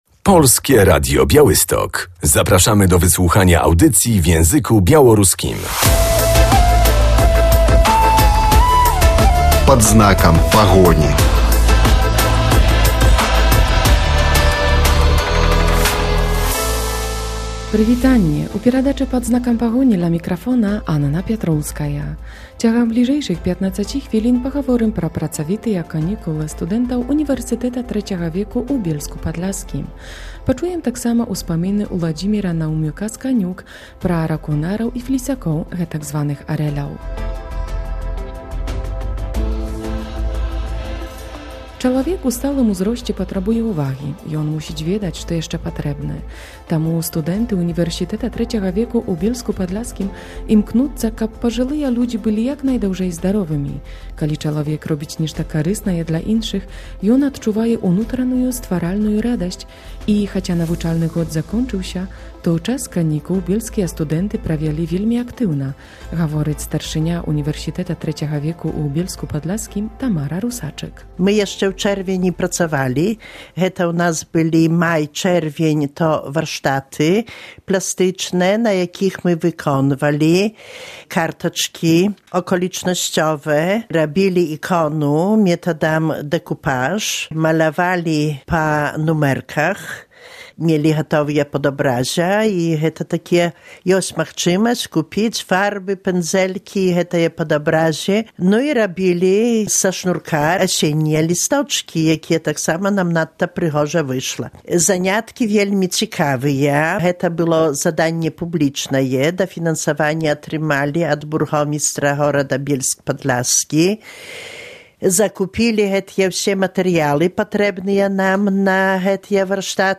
W audycji porozmawiamy o aktywnej działalności studentów UTW w Bielsku Podlaskim.